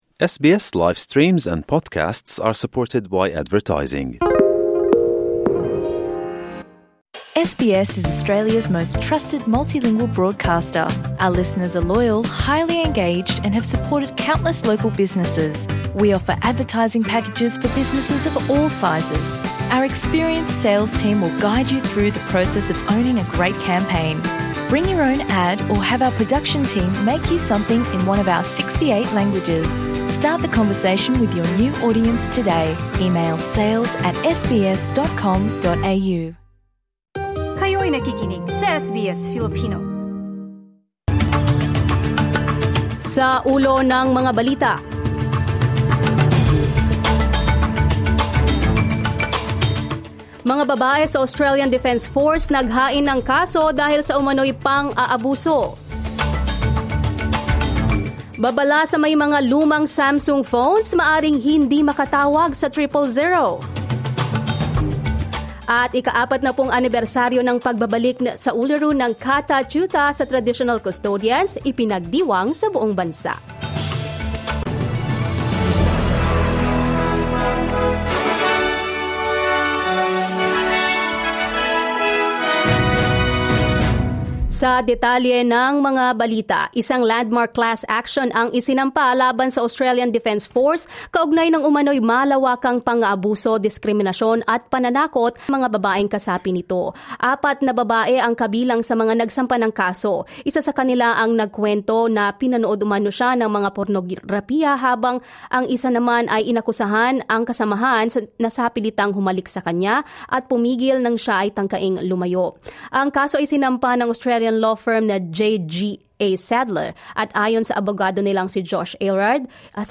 SBS News in Filipino, Saturday 25 October 2025